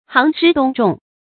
行師動眾 注音： ㄒㄧㄥˊ ㄕㄧ ㄉㄨㄙˋ ㄓㄨㄙˋ 讀音讀法： 意思解釋： 謂指揮大軍作戰。